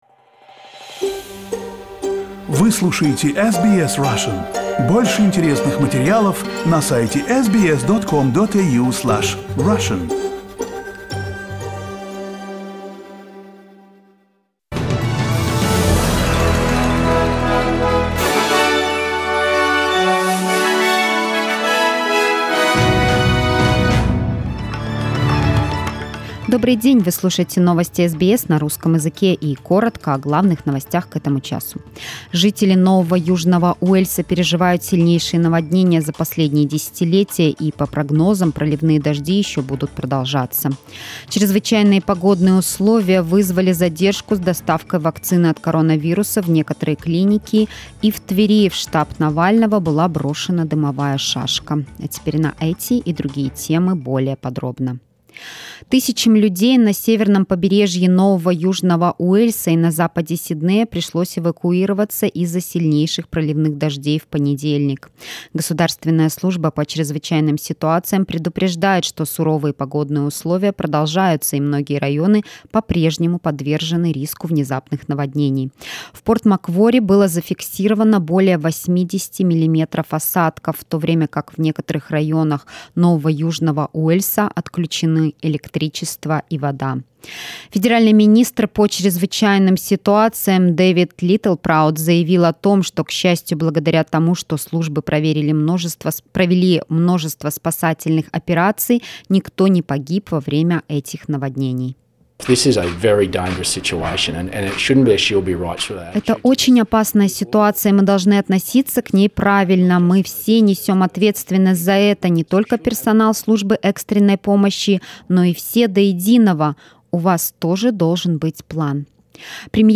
News bulletin March 22nd